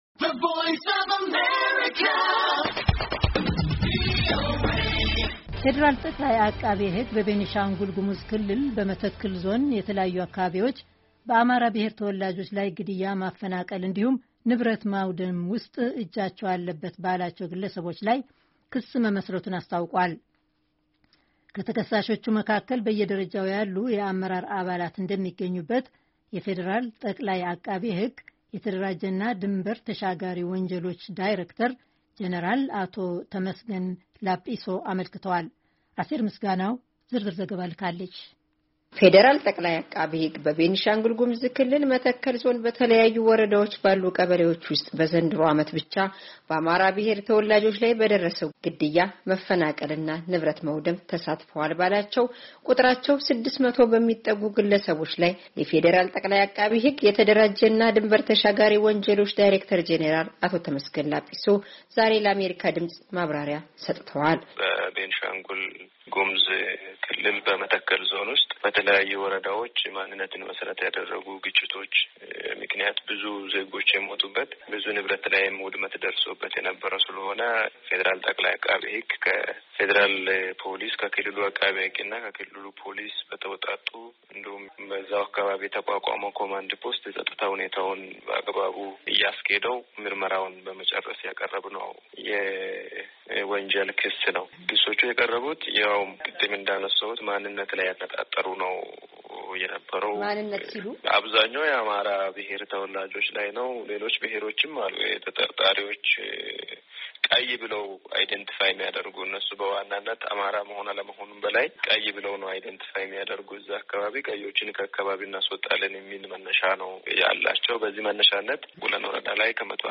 የፌደራል ጠቅላይ ዐቃቤ ሕግ የተደራጀና ድንበር ተሻጋሪ ወንጀሎች ዳሬክተር ጀነራል አቶ ተመስገን ላፒሶ ዛሬ ለአሜሪካ ድምፅ ማብራሪያ ሰጥተዋል።